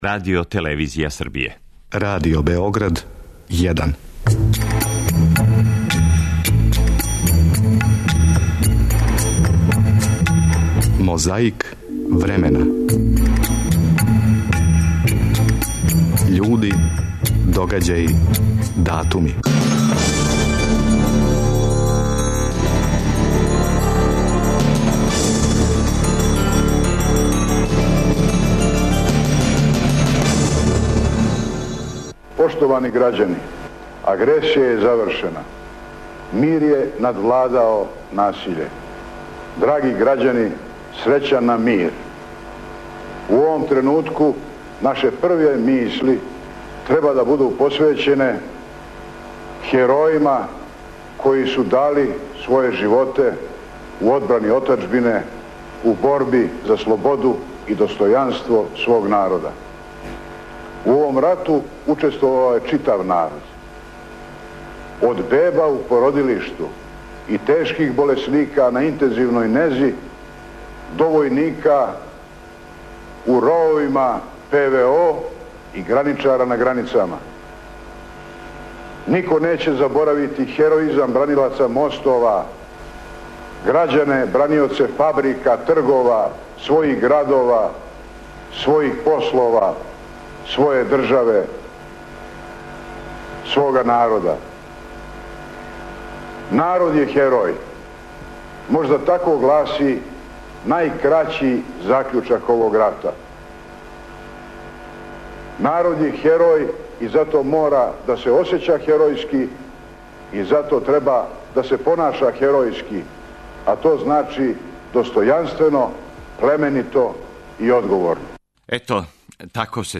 Слушамо записе са његовог суђења, које се данас, ето, сматра контраверзним.
Једна од вреднијих трака у нашој архиви. Деветог јуна 1991. године на београдском Тргу републике одржан је митинг удружене демократске опозиције.
Том приликом прво је положио заклетву, а потом пригодно беседио.